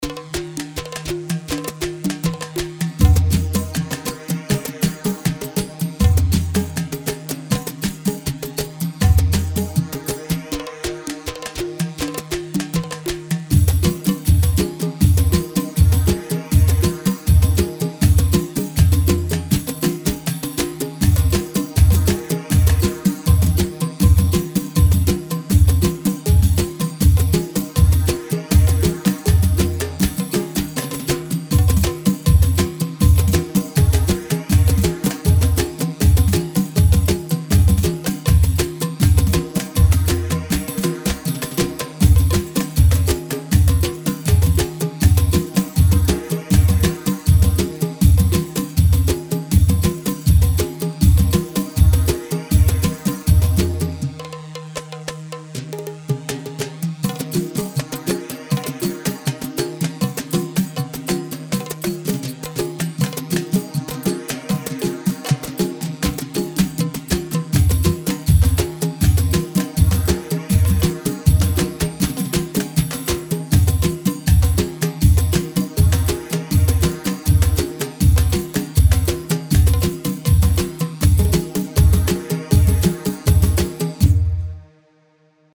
Khbeiti 3/4 120 خبيتي
Khbeiti-3-4-120.mp3